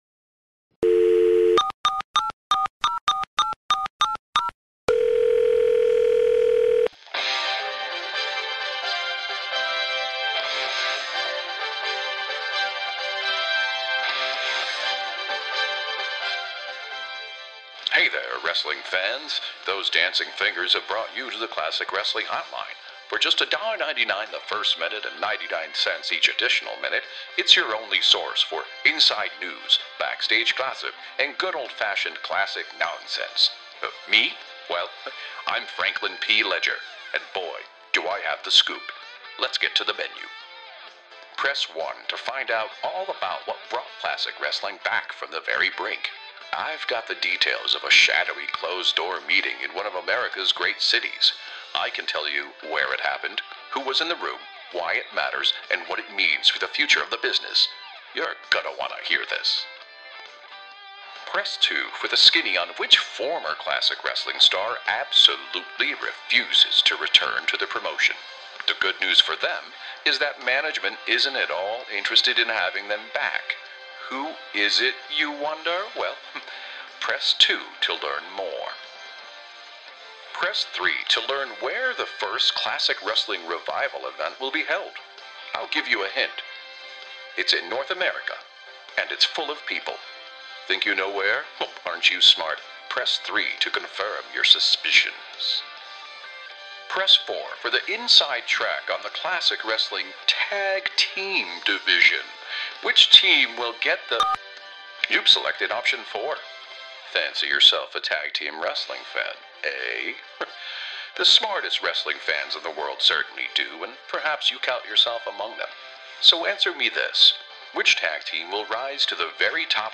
First call-in edition of the Classic Hotline. Quick hits, promos, and show chatter.